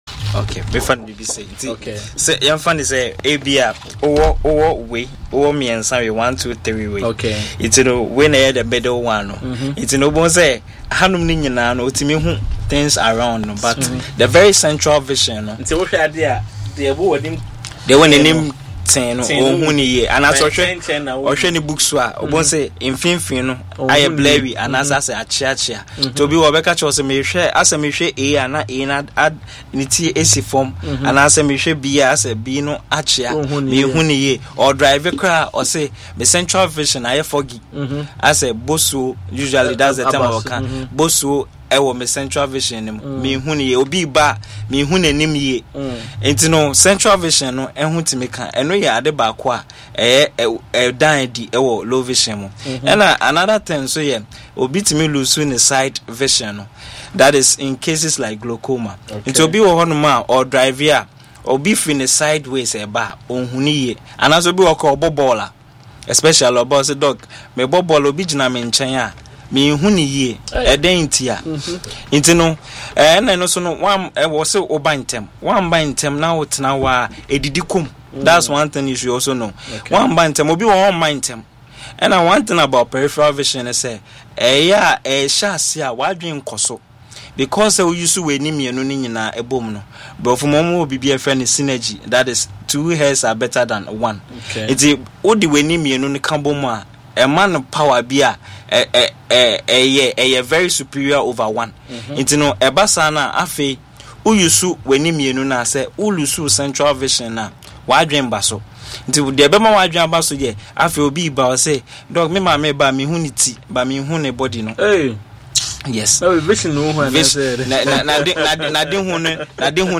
Health and Lifestyle radio show focusing on reproductive health and lifestyle.